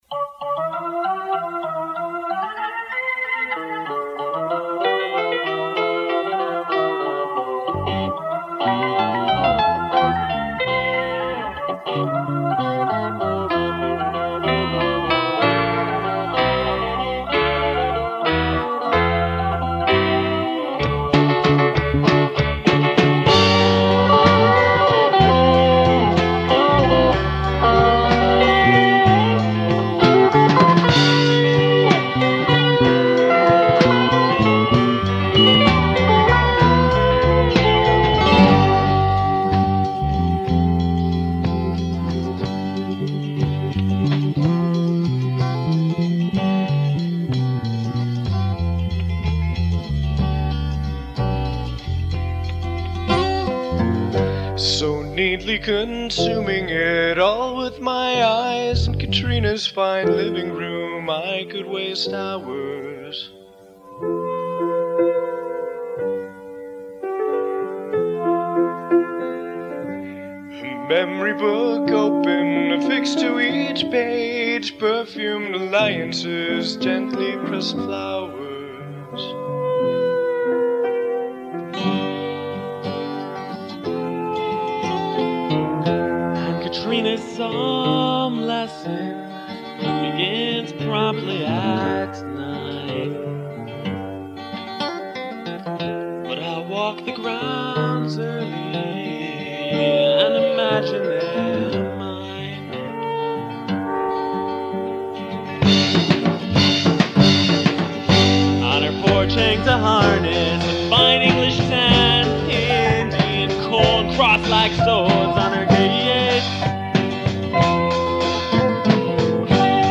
drums and bass
piano and vocals